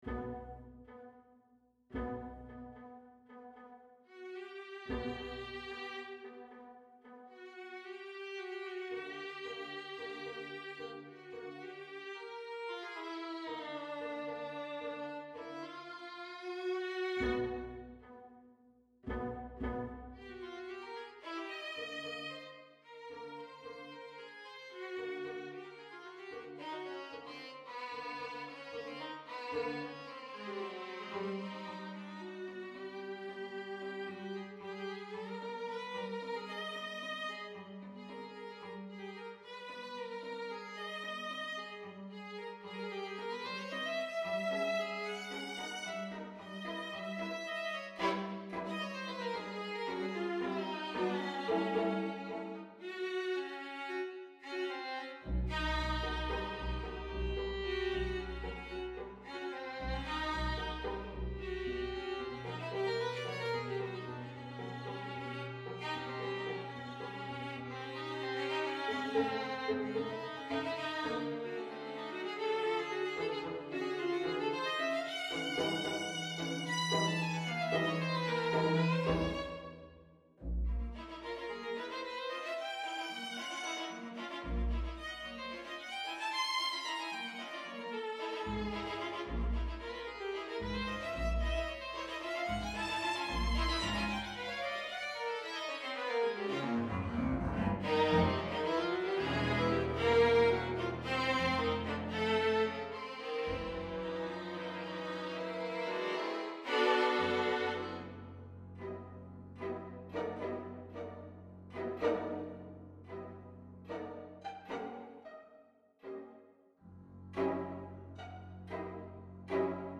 for two Violins, viola, cello and Double bass or String...